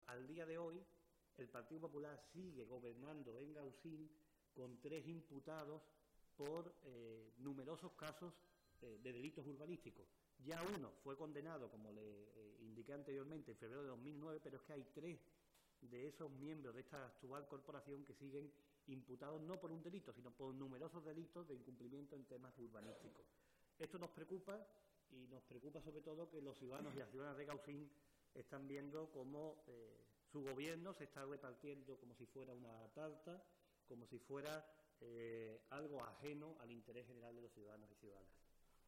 El secretario de Organización del PSOE de Málaga y de Comunicación del PSOE andaluz, Francisco Conejo, ha asegurado hoy en rueda de prensa que la dimisión del alcalde de Gaucín, el popular Francisco Ruiz, "es un ejemplo más del caos y el desconcierto en el que el PP ha sumido el municipio".